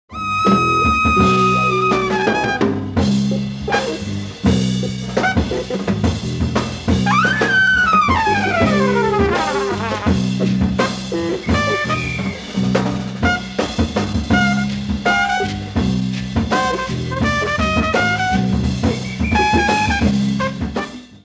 LIVE AT FILLMORE WEST 10/14/1970
サウンドボード録音
完全セパレート・ステレオで収録された音質はマニアには涙モノのレアー音源！
(voiceover announcement)